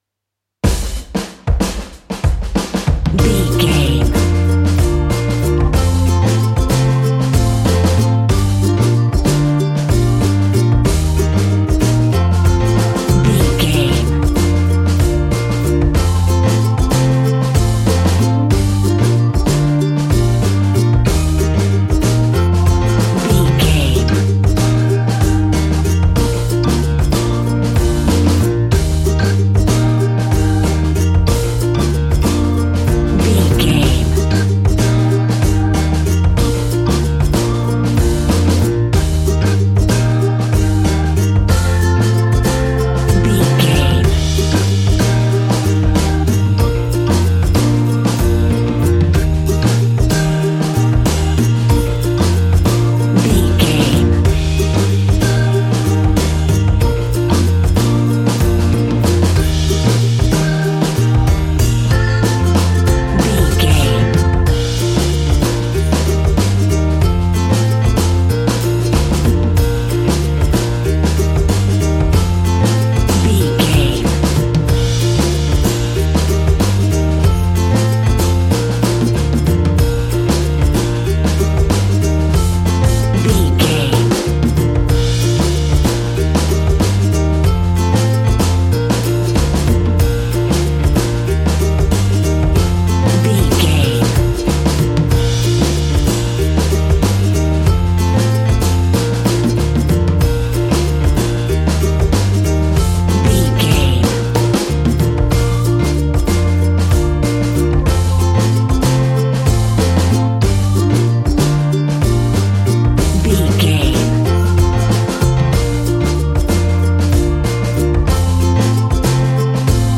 Ionian/Major
B♭
steelpan
worldbeat
drums
percussion
bass
brass
guitar